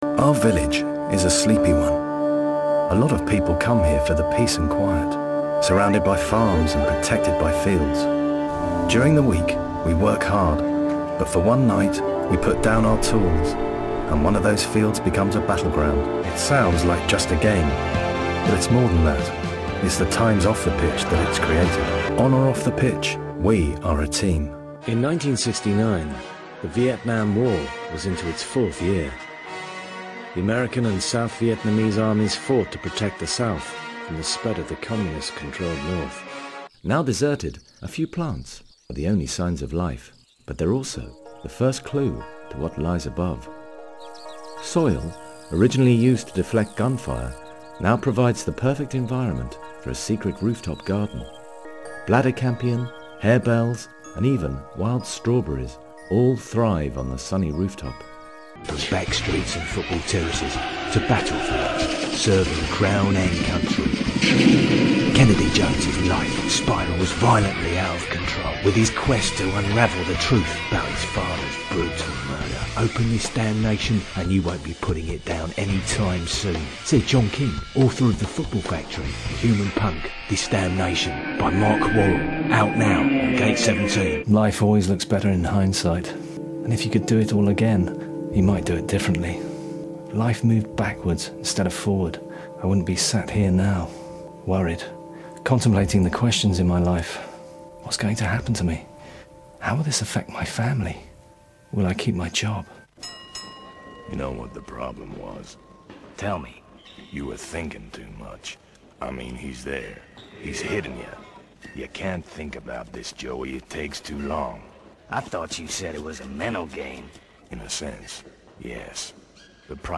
Male
English (British)
Adult (30-50)
He has an assured vocal delivery, with a clear, professional edge.
Documentary
All our voice actors have professional broadcast quality recording studios.